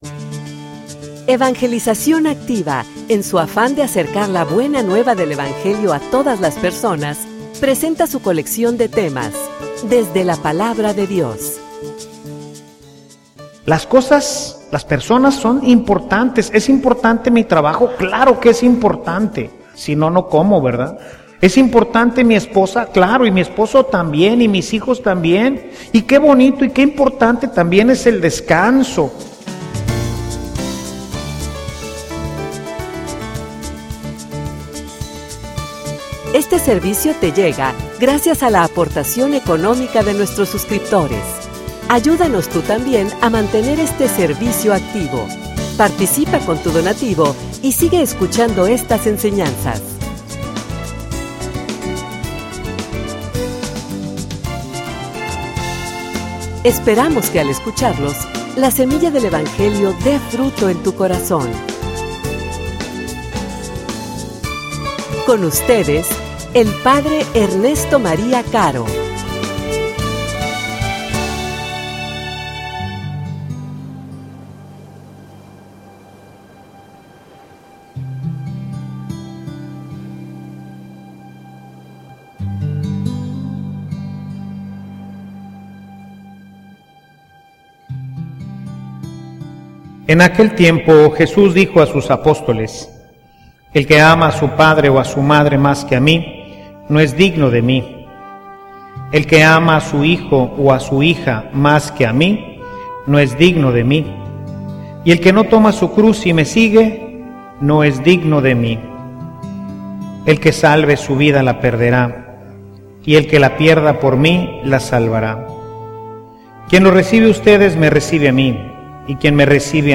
homilia_Cuestion_de_primacia.mp3